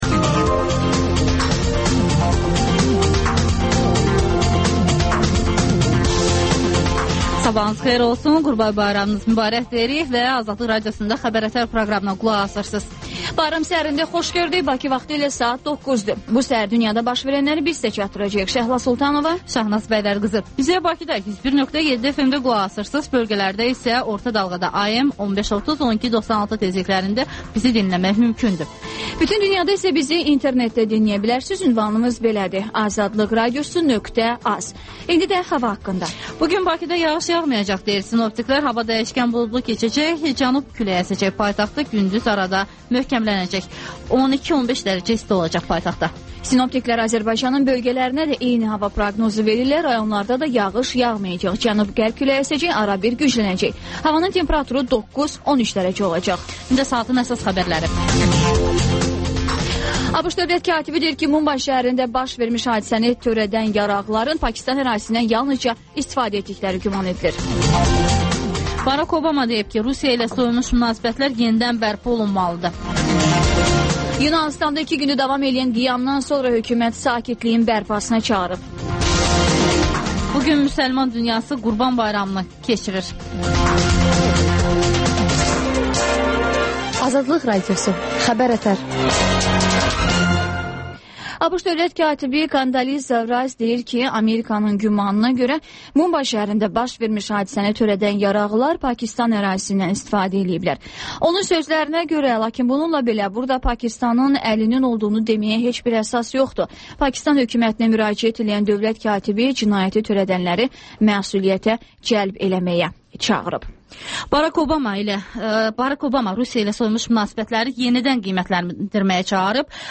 Xəbər-ətər: xəbərlər, müsahibələr, sonra TANINMIŞLAR rubrikası: Ölkənin tanınmış simaları ilə söhbət